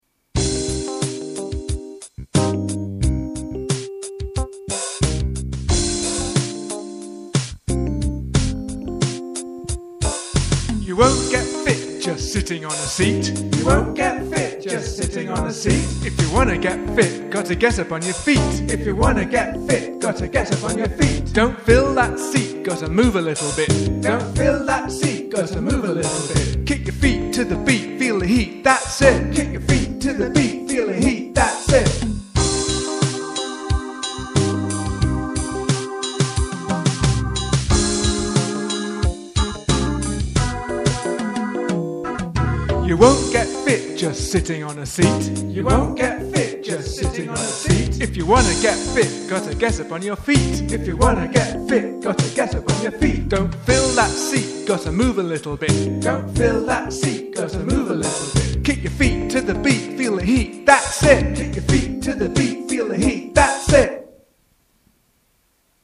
You'll find the slides on a pdf below, and an MP3 of the rap, and a second karaoke version.
Keep Fit rap with repetitions.mp3